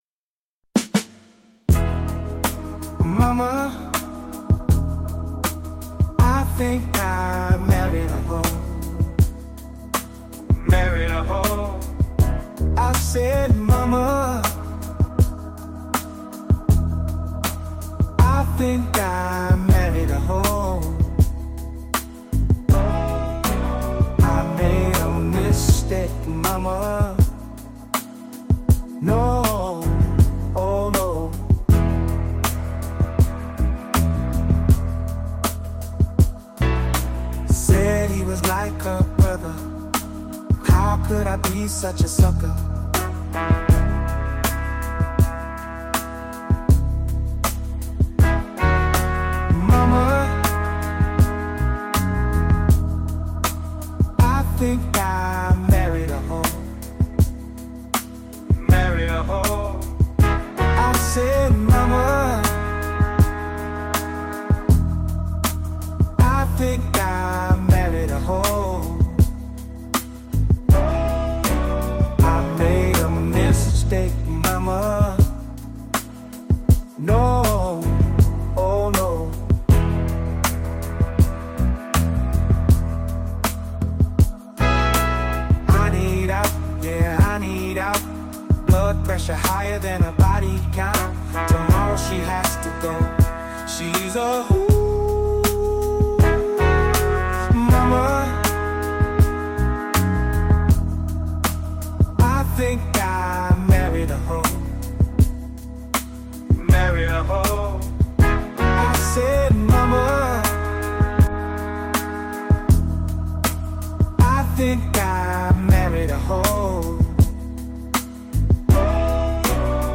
yeah i like this one a lot, even though it's really simple, it sounds chill but it's maybe i bit repertoire on the same mamma bit lol, outside of that it's just a real chill song with a great beat and message even tough it's such a small message,
got to love those lyrics after the guitar solo.